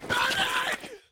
stunned.ogg